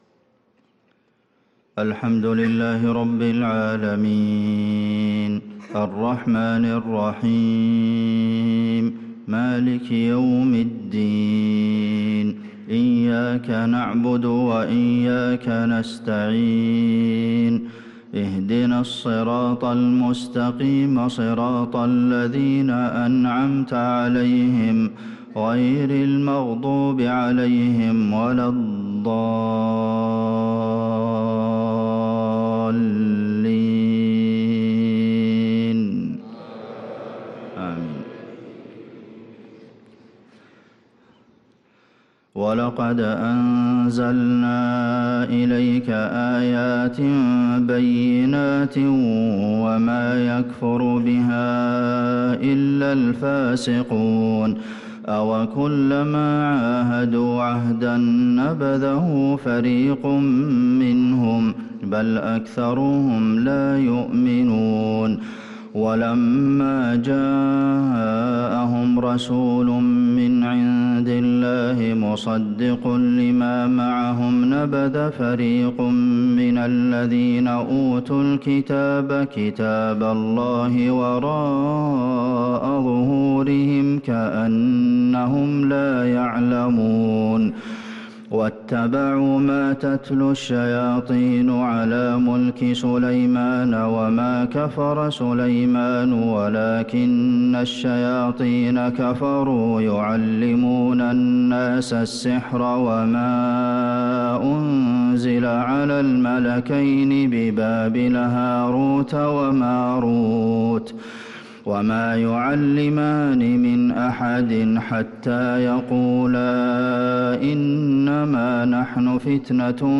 صلاة الفجر للقارئ عبدالمحسن القاسم 22 شعبان 1445 هـ
تِلَاوَات الْحَرَمَيْن .